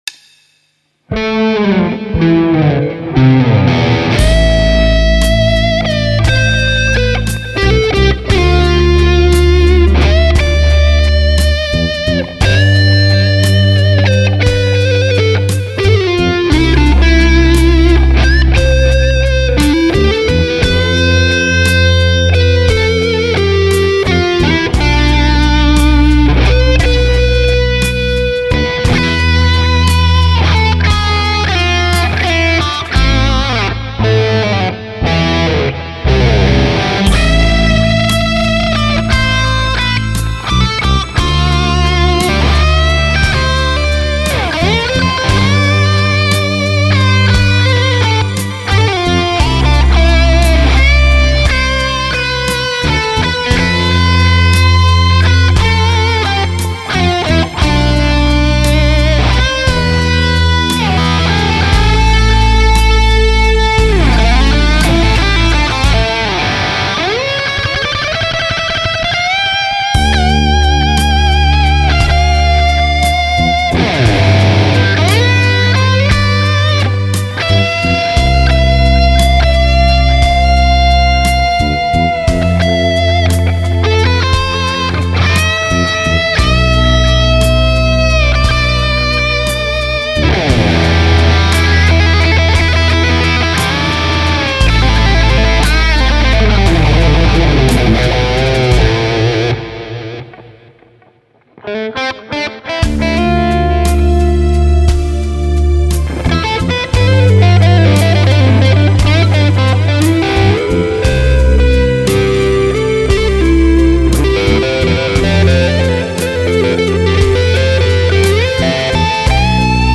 The recording is all direct Axe FX.